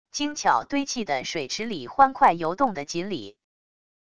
精巧堆砌的水池里欢快游动的锦鲤wav音频